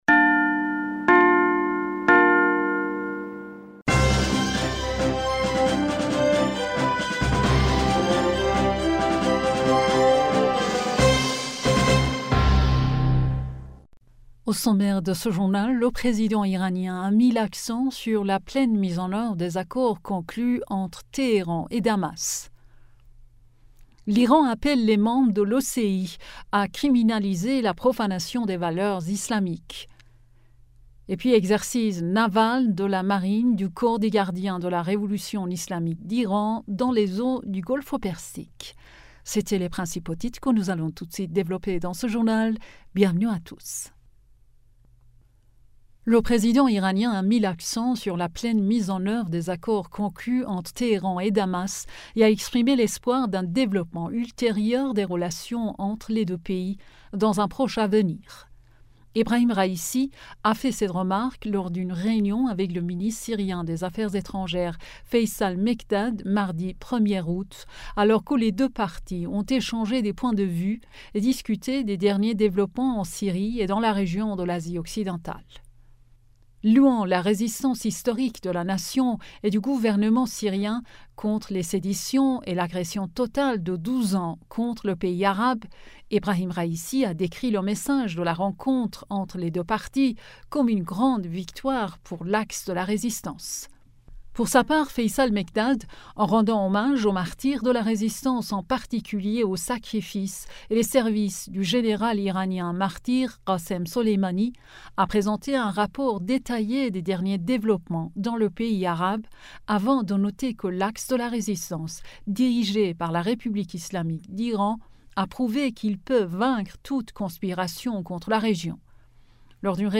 Bulletin d'information du 02 Aout 2023